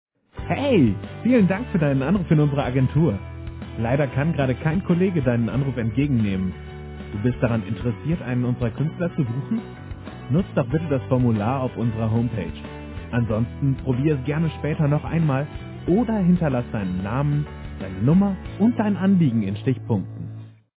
Die Deutsche Stimme mit Variation und viel Charakter
Kein Dialekt
Sprechprobe: Sonstiges (Muttersprache):